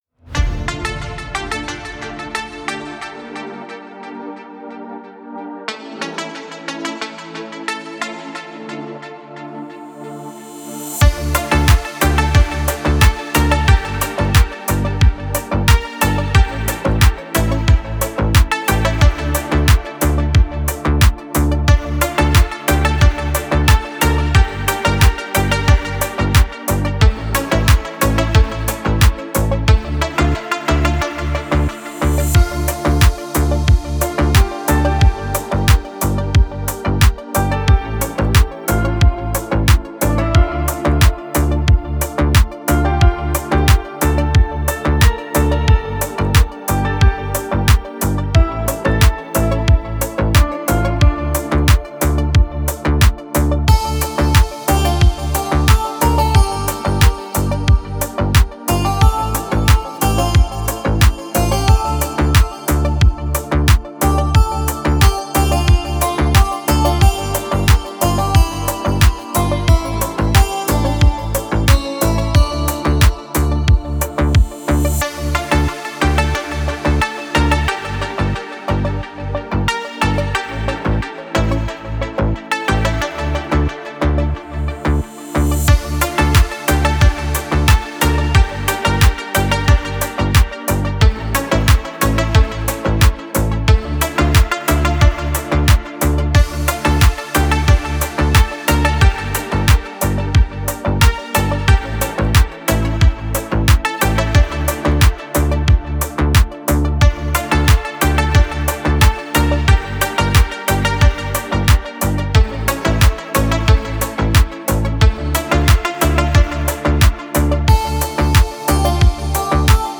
Красивый мелодичный саунд
красивая музыка без слов